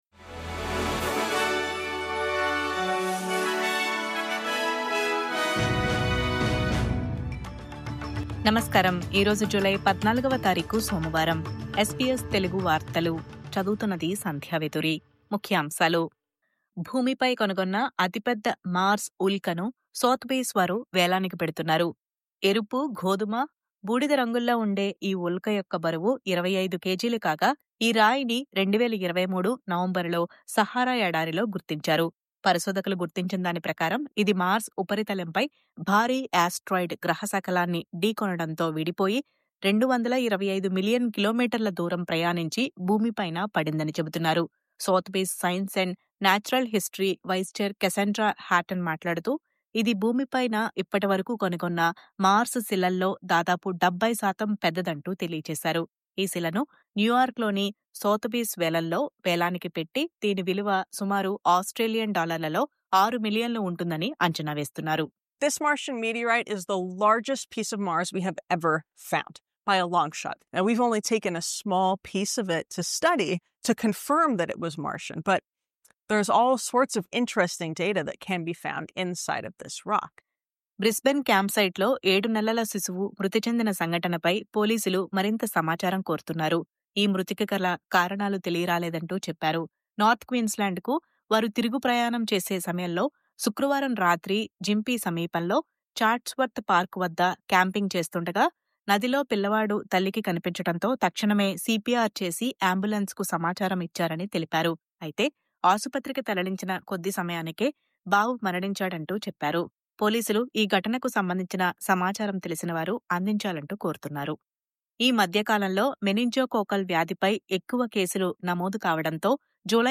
News Update: భూమిపై దొరికిన అతిపెద్ద మార్స్ శిలను వేలం – 60 లక్షల డాలర్లు ఉండొచ్చని అంచనా..